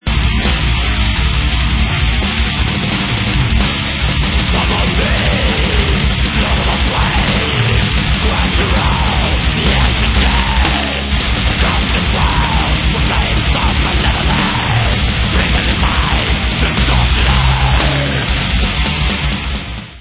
sledovat novinky v oddělení Black Metal